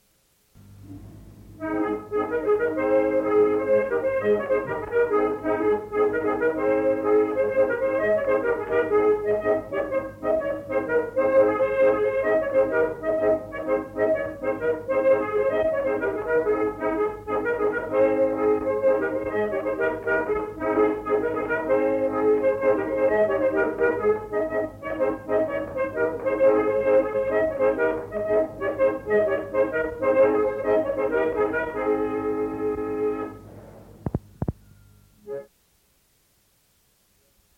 Genre : morceau instrumental
Instrument de musique : accordéon diatonique
Danse : rondeau